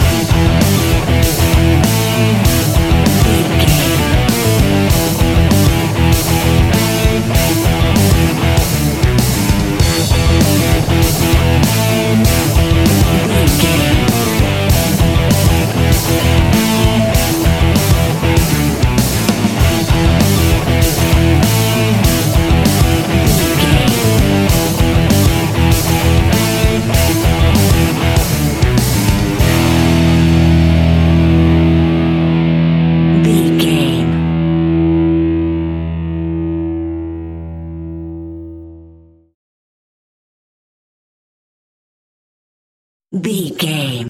Ionian/Major
energetic
driving
heavy
aggressive
electric guitar
bass guitar
drums
hard rock
heavy metal
distortion
instrumentals
distorted guitars
hammond organ